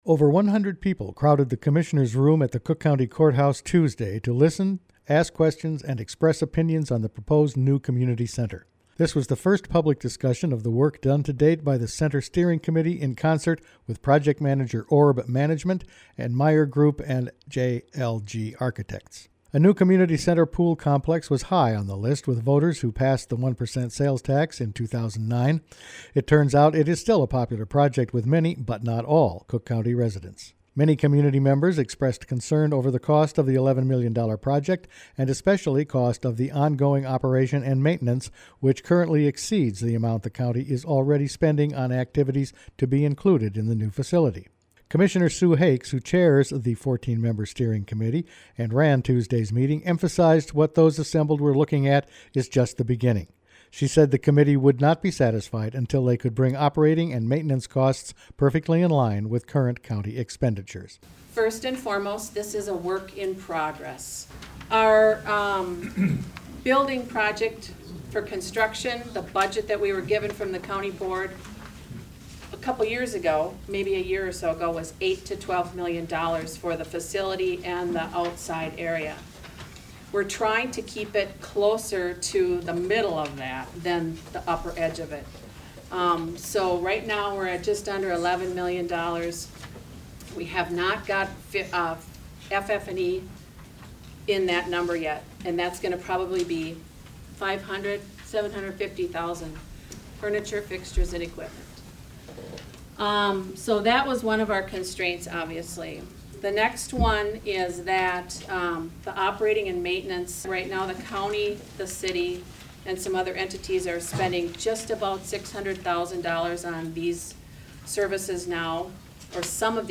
Over 100 people crowded the Commissioners Room at the Cook County Courthouse Tuesday to listen, ask questions and express opinions on the proposed new community center. This was the first public discussion of the work done to date by the center steering committee in concert with project manager ORB Management and Meyer Group and JLG Architects.